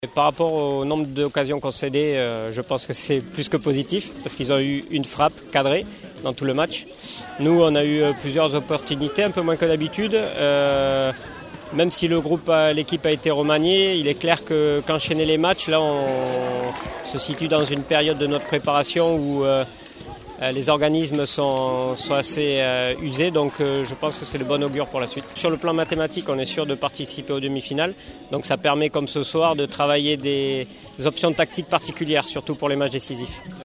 L’interview